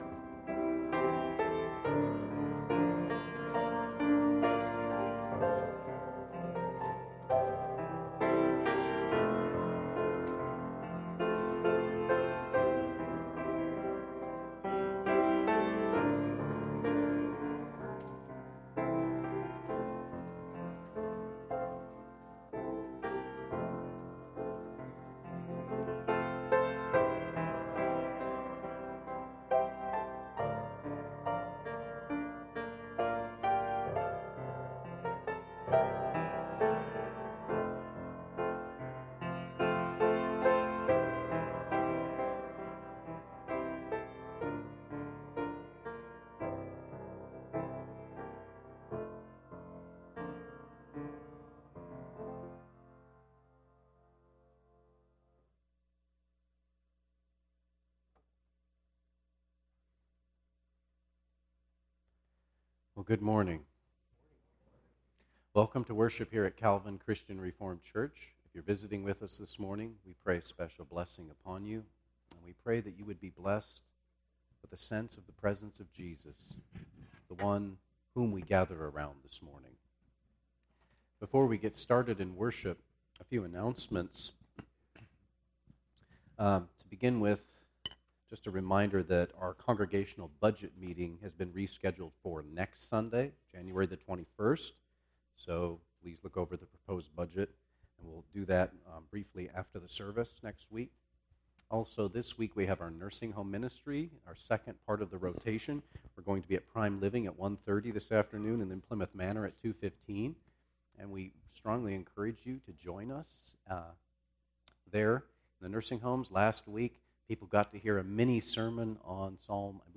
Sermons | Calvin Christian Reformed Church